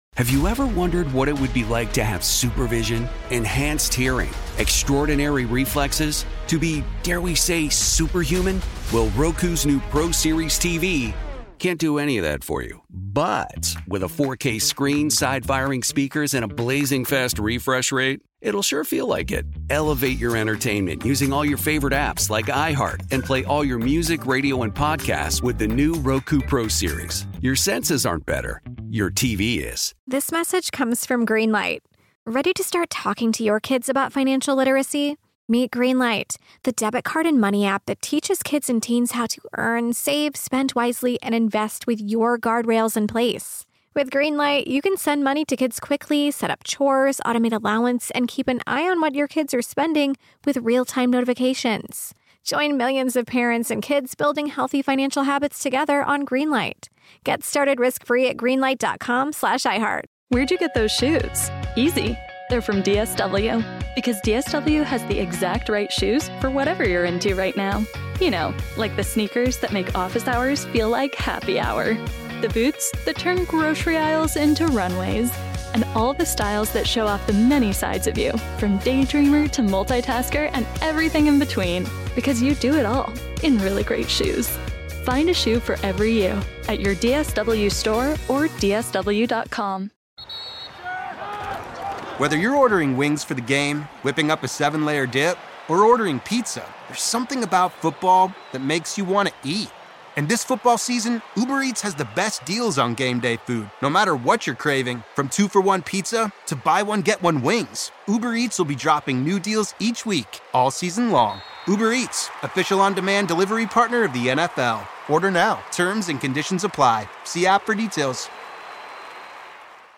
On this episode of Our American Stories, the story of the woman who said "no" to giving up her seat on a segregated Alabama bus is widely known. But here to tell the raw, real story, is Rosa Parks herself.